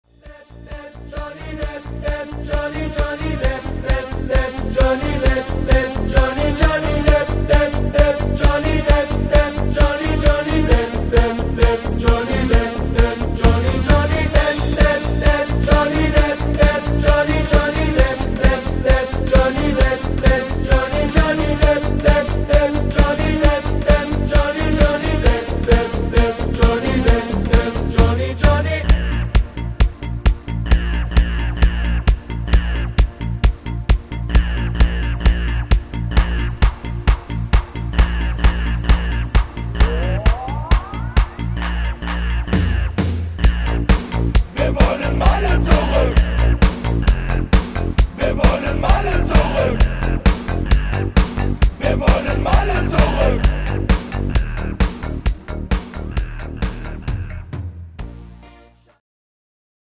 Rhythmus  Party Disco
Art  Deutsch, Mallorca-Songs, Party Hits